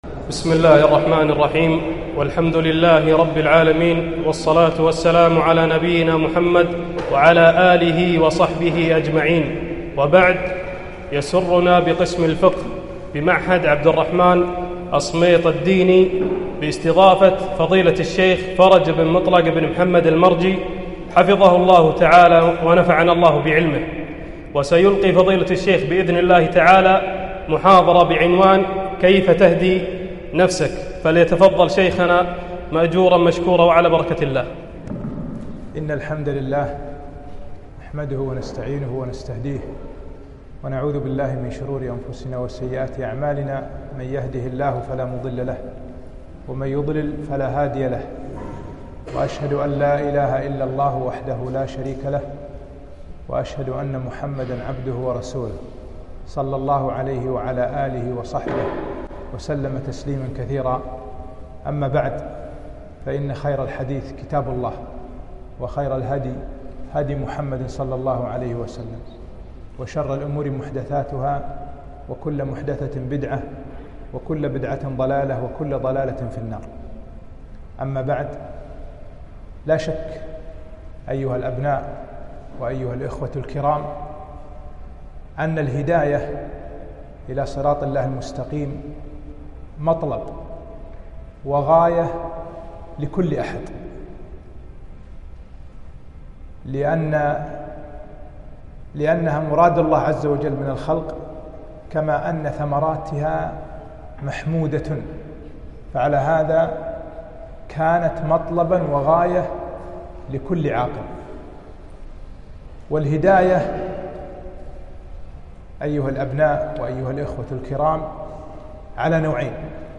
محاضرة - كيف تهدي نفسك